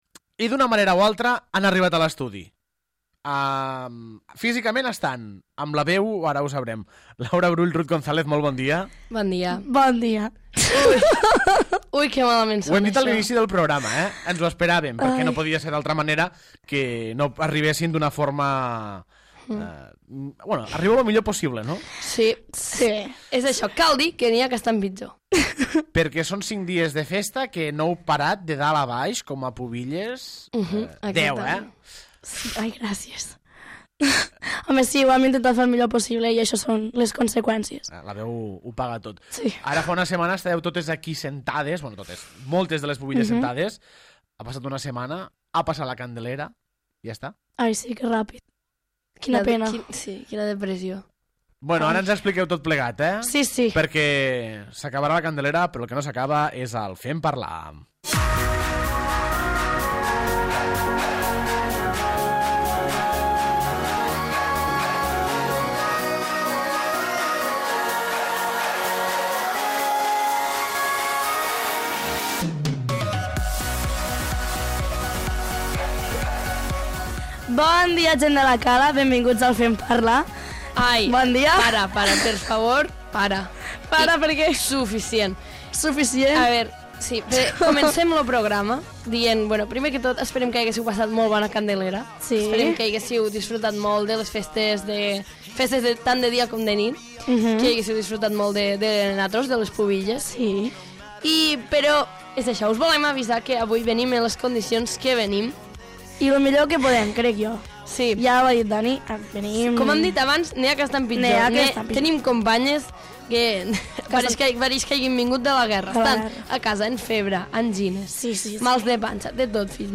Les nostres damisel·les ja han acabat de viure els intensos dies de Candelera, i no podien perdre l'oportunitat d'explicar-ho al Fem parlar. Per cert, no us amoïneu per les seves veus... han dormit poc!